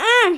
Hurt_grunt.mp3